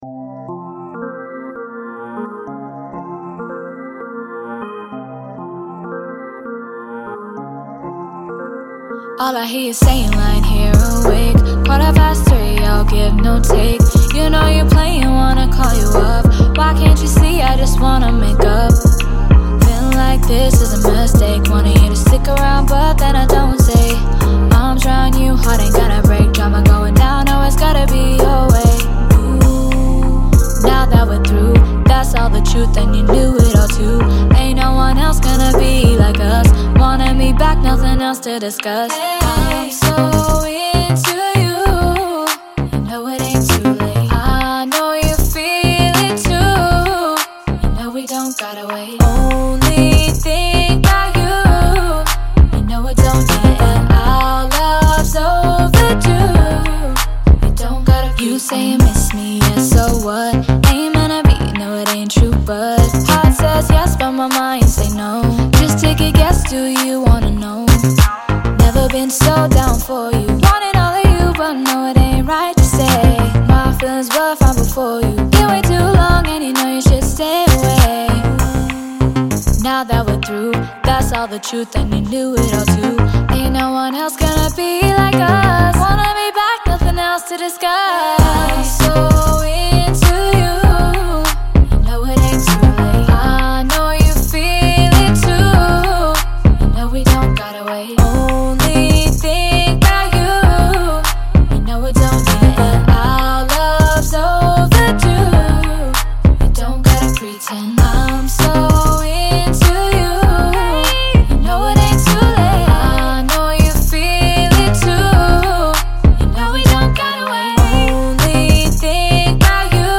# rnbass # rnb # Hip Hop